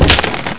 wood2.wav